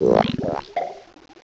sovereignx/sound/direct_sound_samples/cries/pecharunt.aif at 2f4dc1996ca5afdc9a8581b47a81b8aed510c3a8
pecharunt.aif